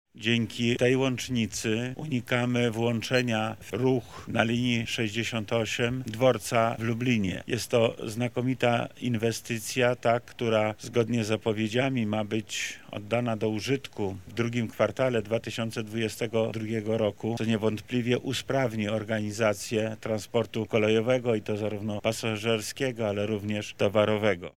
-mówi Wojewoda Lubelski Lech Sprawka.